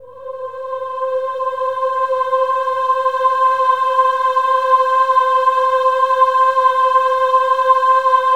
OH-AH  C5 -L.wav